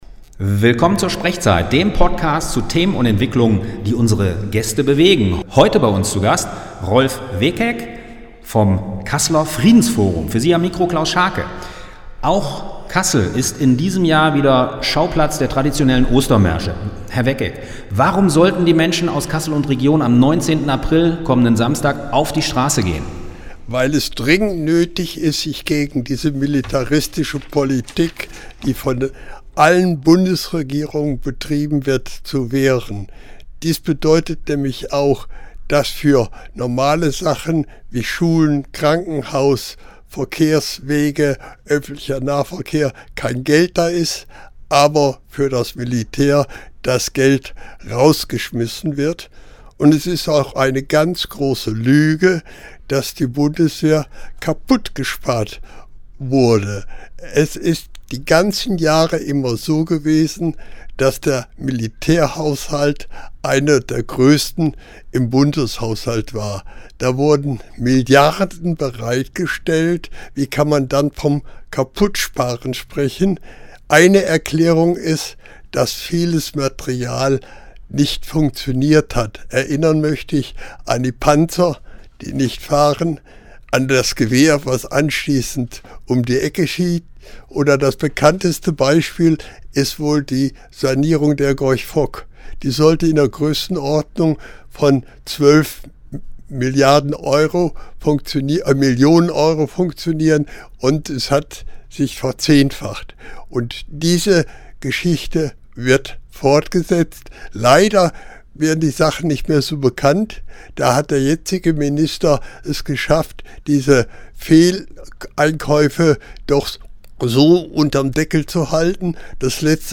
Lassen Sie sich bitte vom Hall nicht irritieren, wenn der Moderator spricht. Da war bei der Aufnahme leider ein Effektfilter eingeschaltet…
Interview